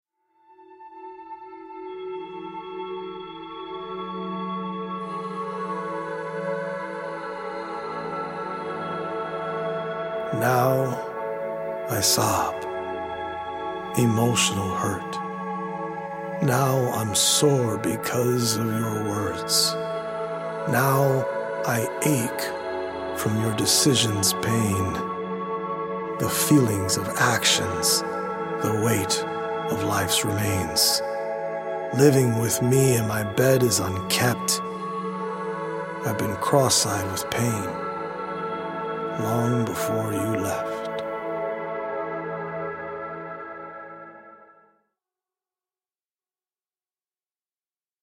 audio-visual poetic journey through the mind-body and spirit
healing Solfeggio frequency music